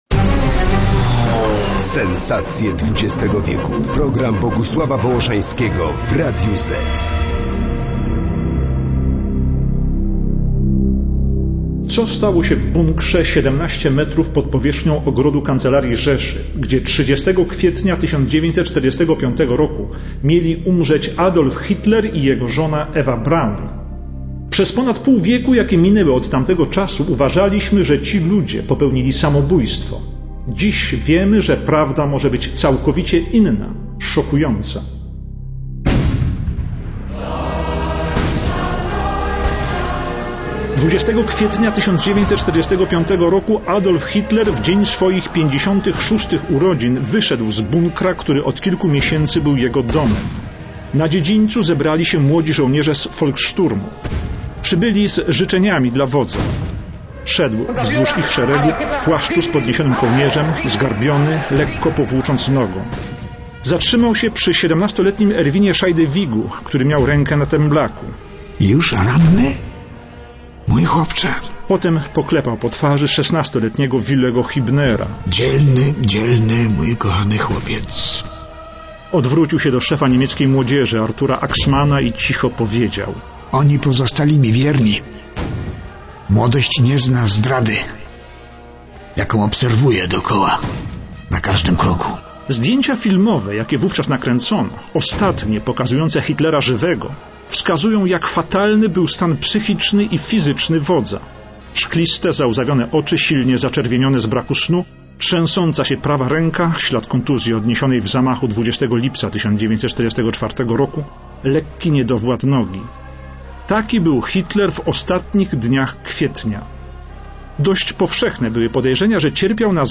Adolf Hitler - Jan Peszek
Karl Weidling dowódca obrony Berlina - Witold Pyrkosz
Heinz Linge kamerdyner Hitlera - Marek Perepeczko
Pilot fuhrera Hans Baur - Krzysztof Globisz
żołnierz radziecki - Jerzy Bończak